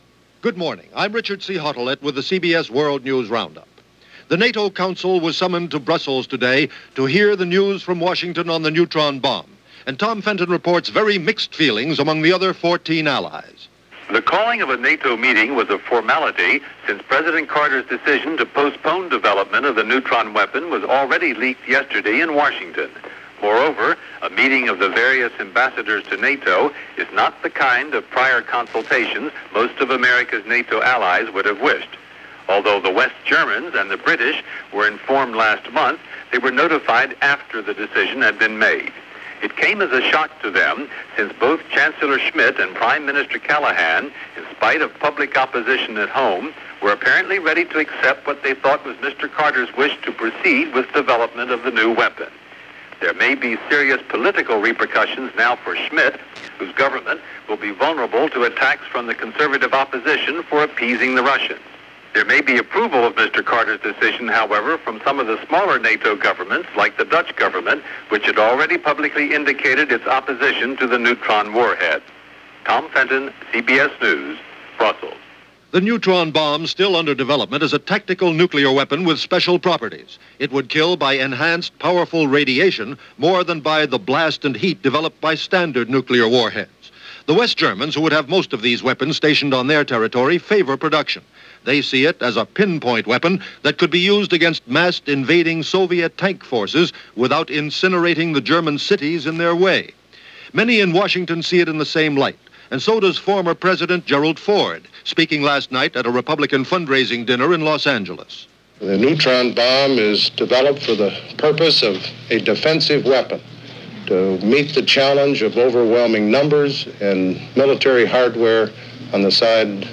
And while debate was going on over the fate of the Neutron Bomb, that’s a small slice of what happened this April 7th from the CBS World News Roundup.